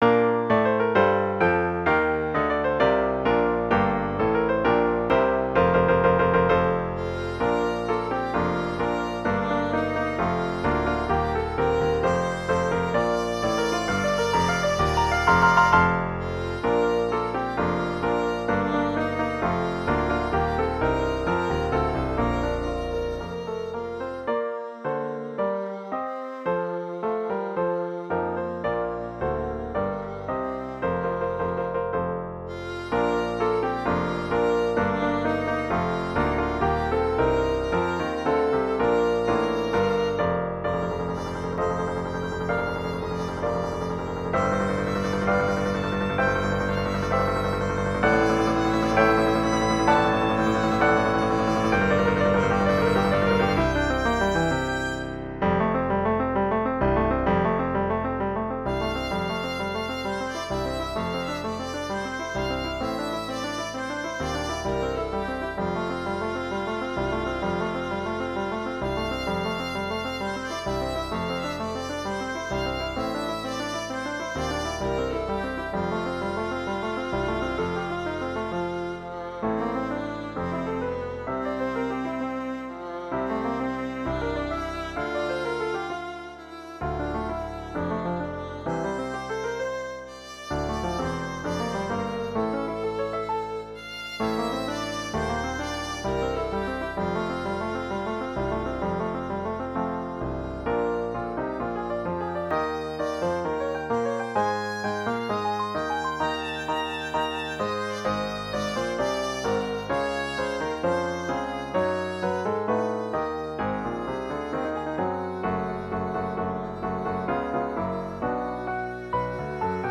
back to the classical style of Haydn, Mozart, and Beethoven